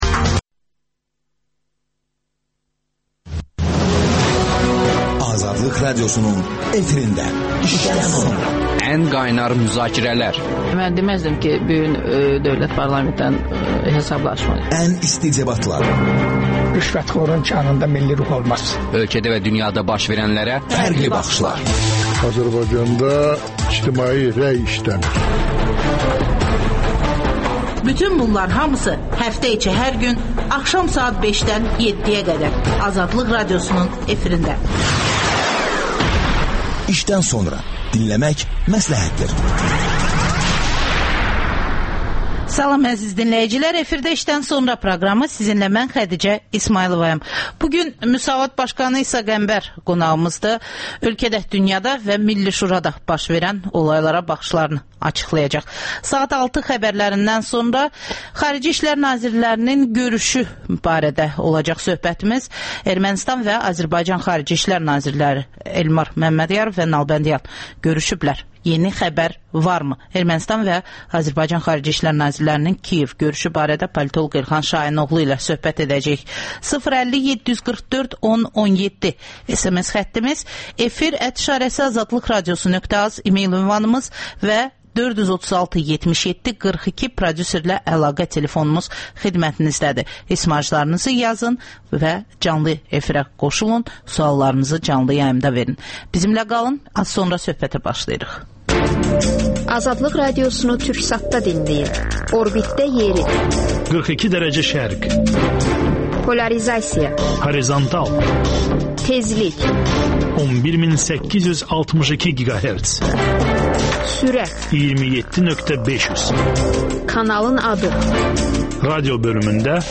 İşdən sonra - İsa Qəmbər suallara cavab verir